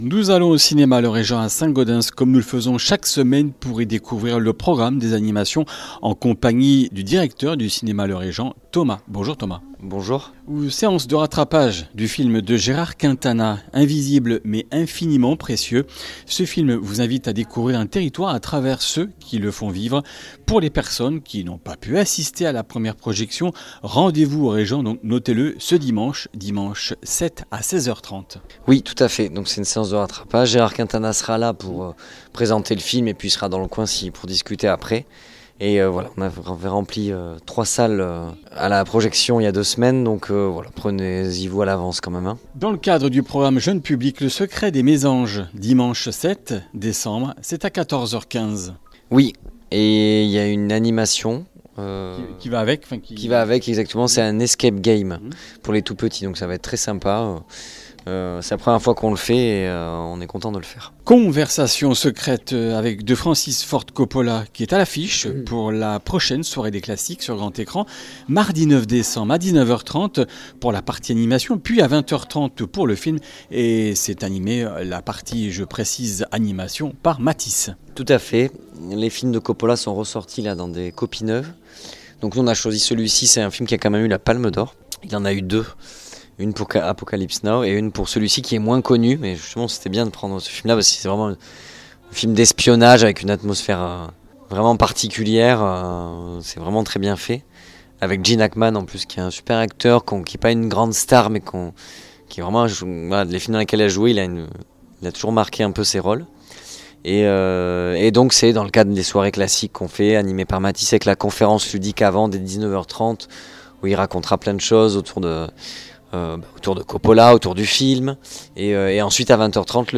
Comminges Interviews du 04 déc.
Une émission présentée par
Journaliste